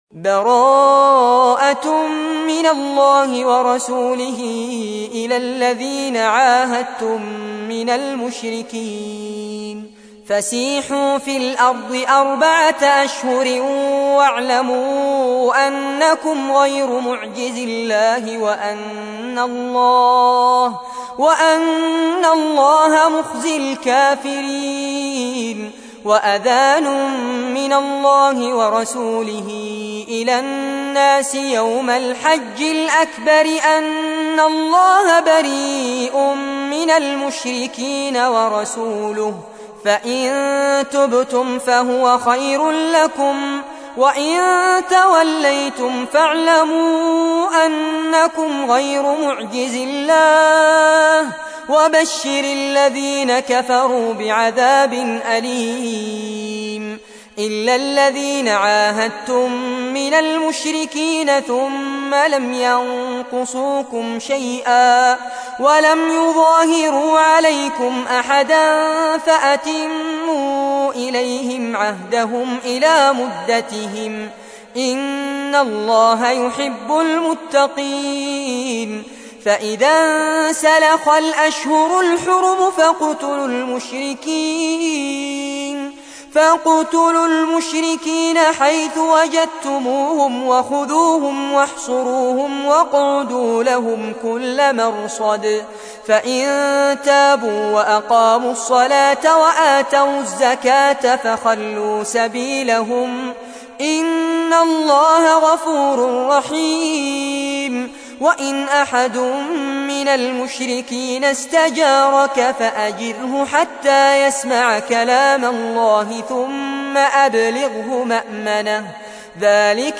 تحميل : 9. سورة التوبة / القارئ فارس عباد / القرآن الكريم / موقع يا حسين